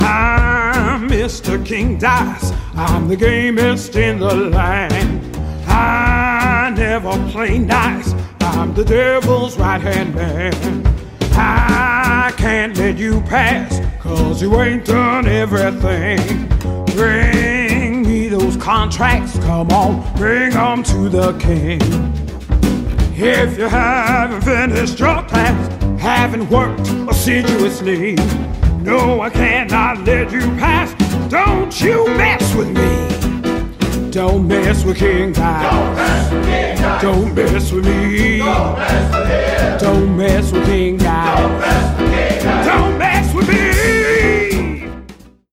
• Качество: 320, Stereo
гитара
веселые
инструментальные
джаз
Blues
барабаны
Трек в стиле джаз и блюз